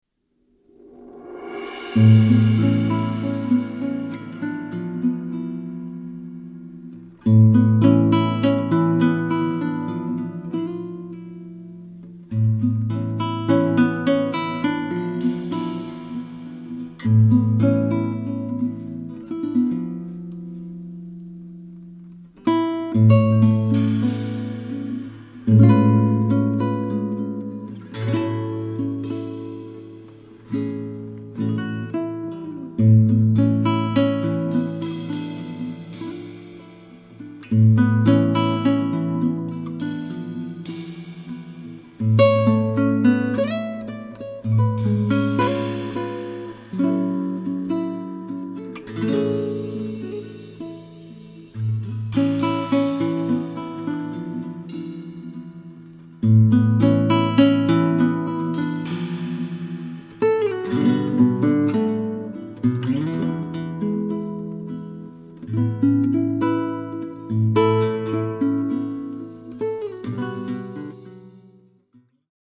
chitarra
percussioni